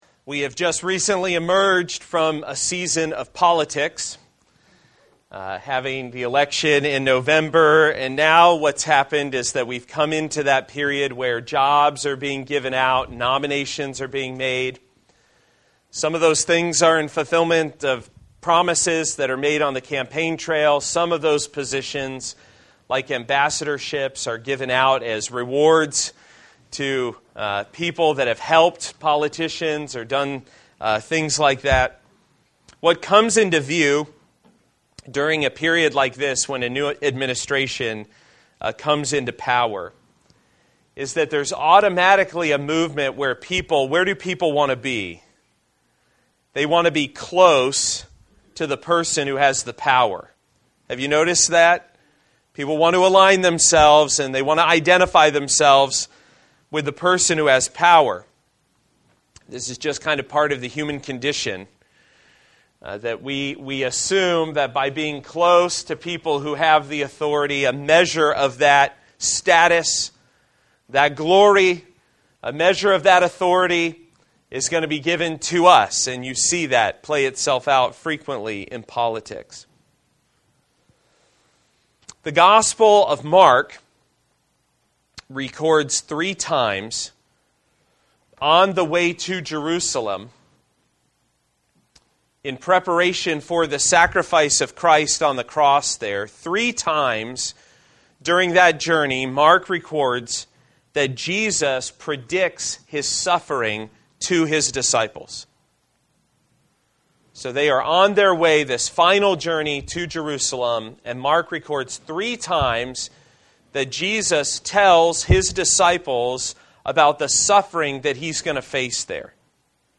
A message from the series "Communion."